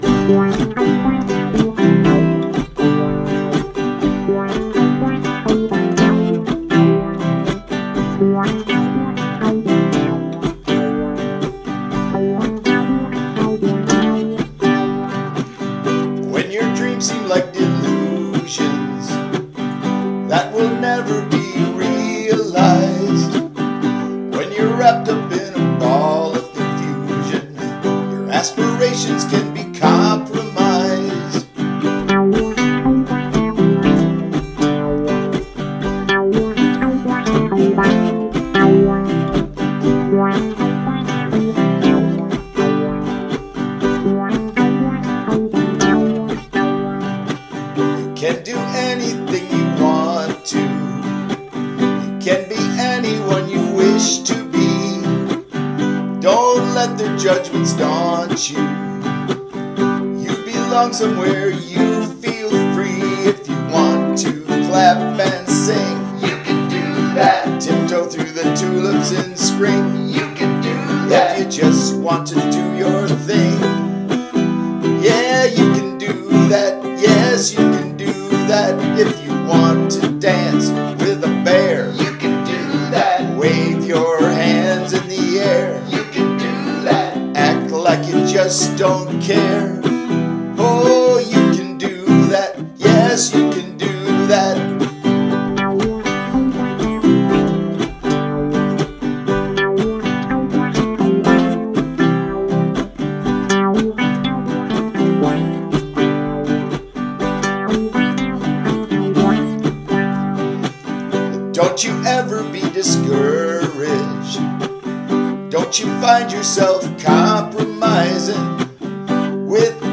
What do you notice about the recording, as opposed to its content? Recorded completely on my new Audigo microphone; this doesn’t do it justice, but I recorded an fingerpicking guitar concert over the weekend and it sounded amazing!